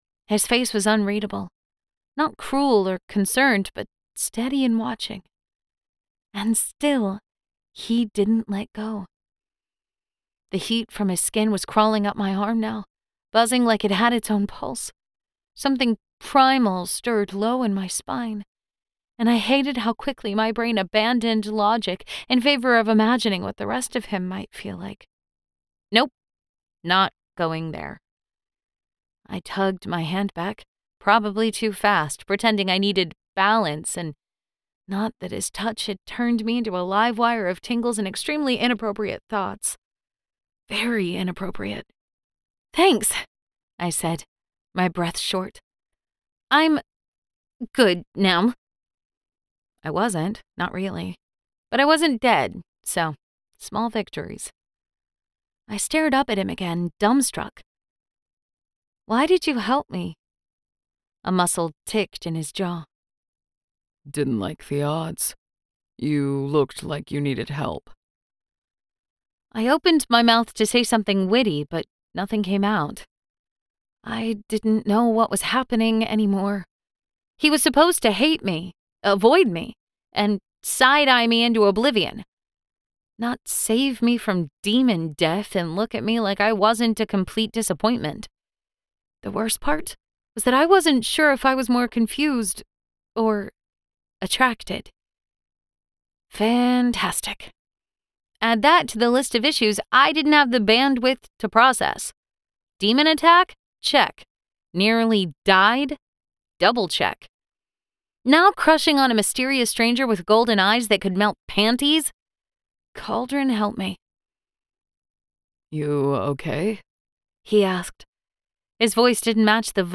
Classic Warmth, Modern Confidence
Audiobook Narration - Romantasy - Cute Comedy
Romantasy Comedy.mp3